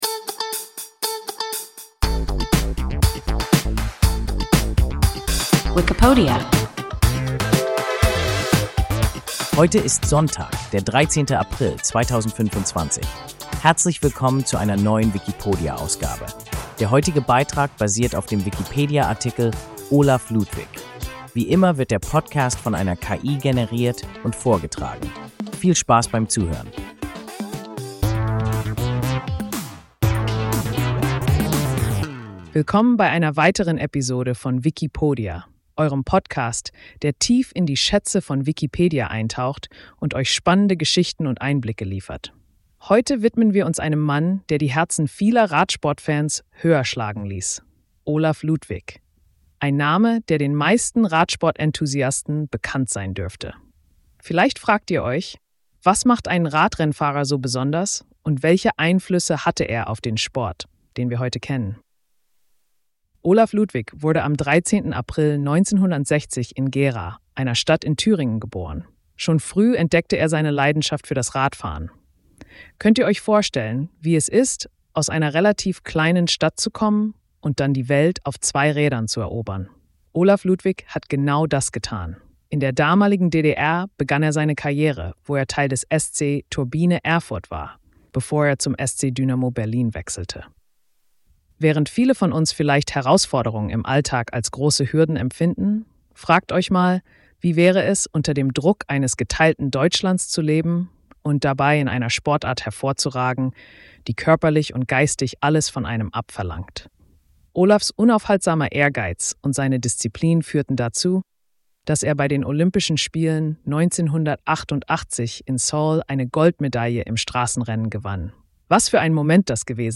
Olaf Ludwig – WIKIPODIA – ein KI Podcast